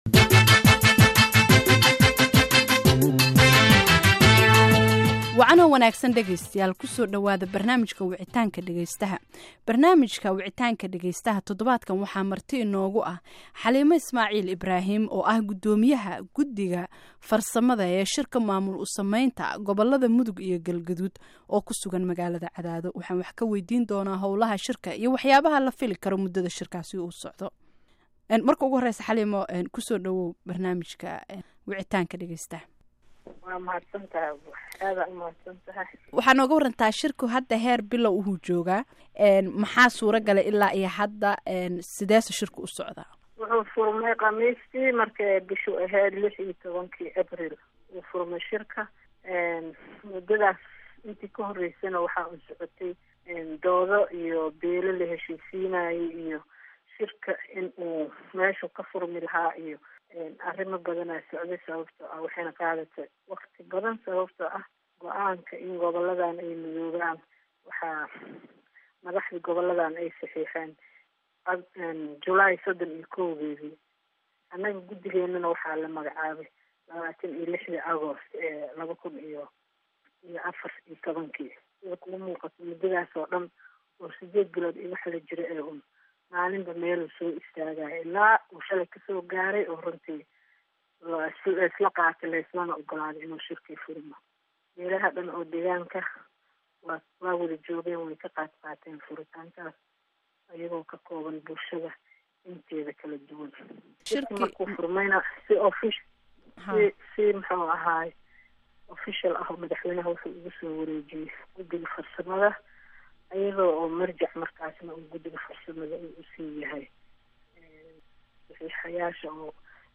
Barnaamijka wicitaanka dhegeystaha e eka baxa idaacada afka Soomaaliga ku baxda ee VOA ayaa waxaa todobaadkan marti ugu ahaa gudoomiyaha gudiga farsamada shirka maamul u sameynta gobolada Koonfurta Mudug iyo Galgaduud, Xaliimo Ismaaciil Ibraahim oo ku sugan magaalada Cadaado.